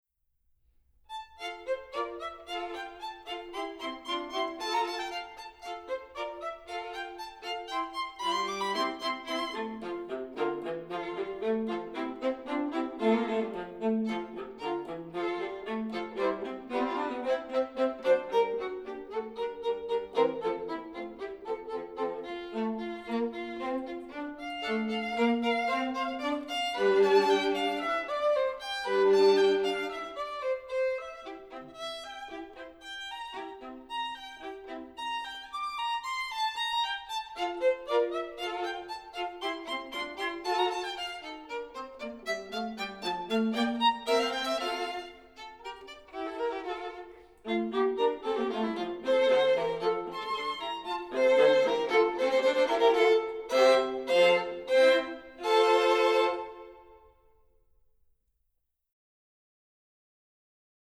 Voicing: 3 Strings